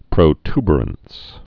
(prō-tbər-əns, -ty-, prə-)